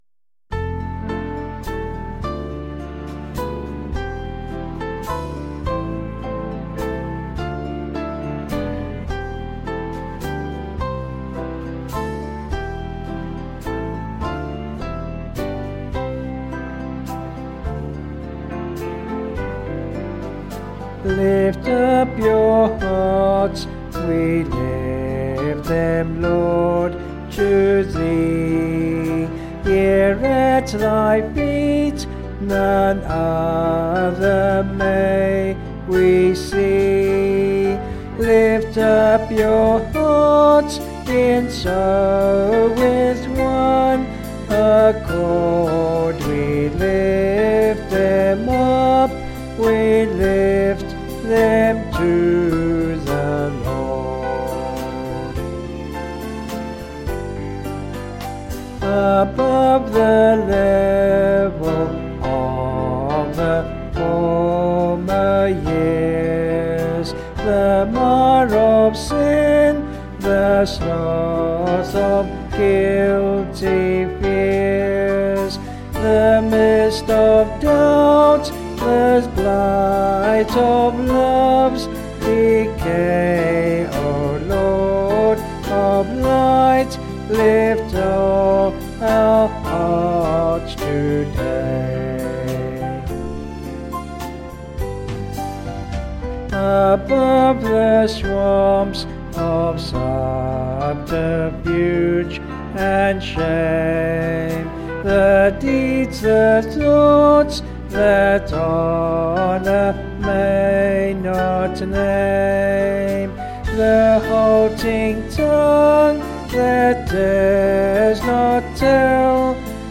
Vocals and Band   264.1kb Sung Lyrics